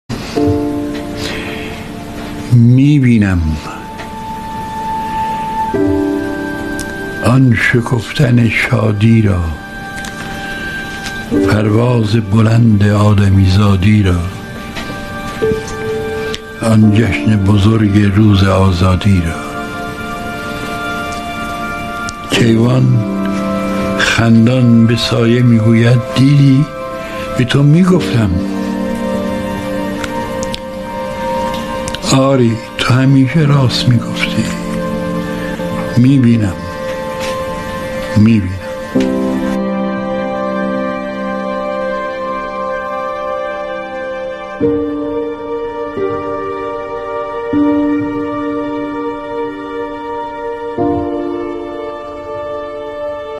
دانلود دکلمه «می‌بینم آن شکفتن شادی را» با صدای «هوشنگ ابتهاج»
برچسب ها: دکلمه هوشنگ ابتهاج